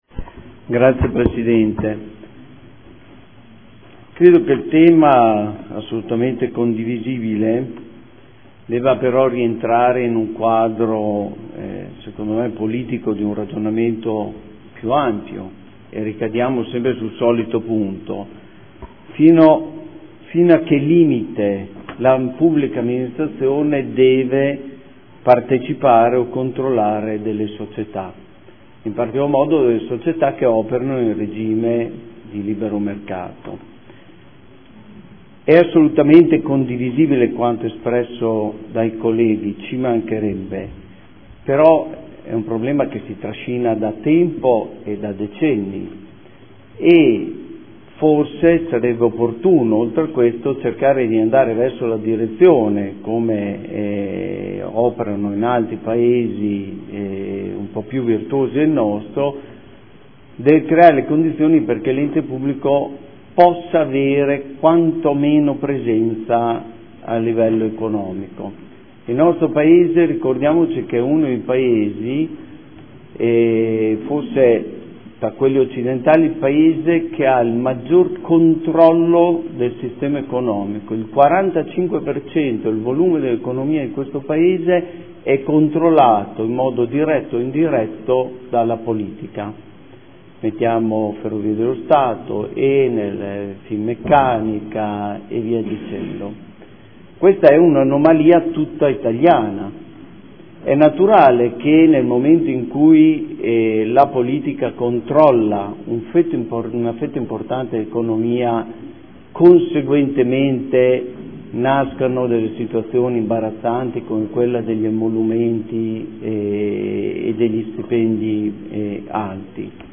Antonio Montanini — Sito Audio Consiglio Comunale
Seduta del 05/02/2015 Dibattito. Definizione degli indirizzi per la nomina e la designazione dei rappresentanti nel Comune presso Enti, Aziende, Istituzioni e Società partecipate